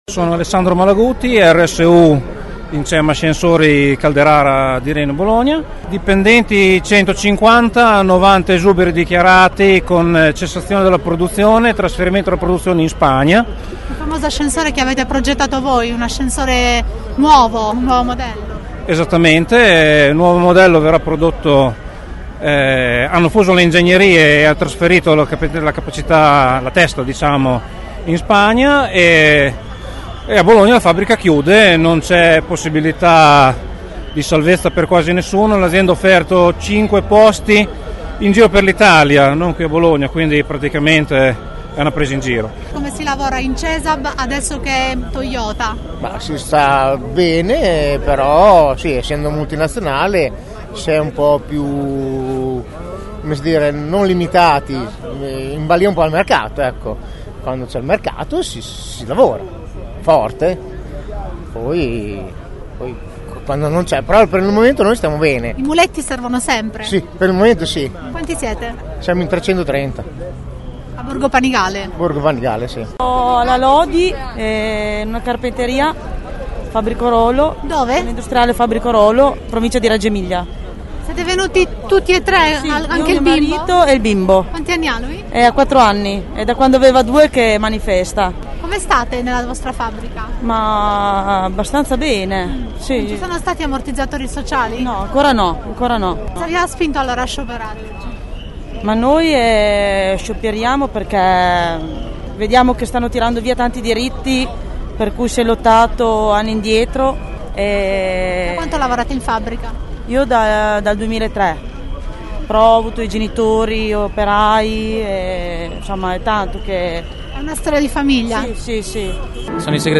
Ascolta le voci di alcuni lavoratori che hanno partecipato alla manifestazione